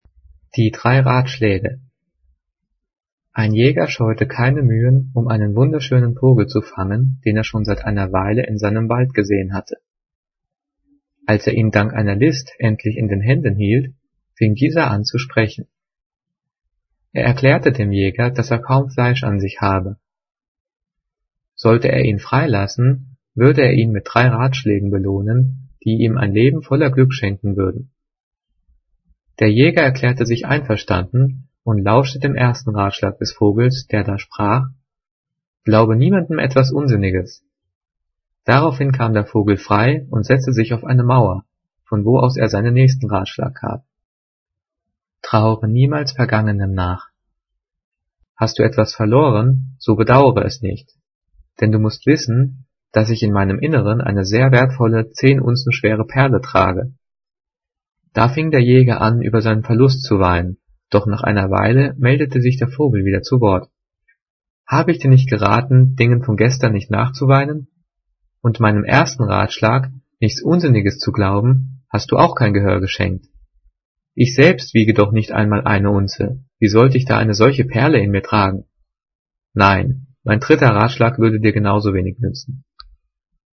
Gelesen:
gelesen-die-drei-ratschlaege.mp3